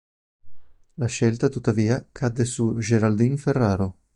Read more Noun Adj Verb Frequency A2 Hyphenated as scél‧ta Pronounced as (IPA) /ˈʃel.ta/ Etymology From scegliere.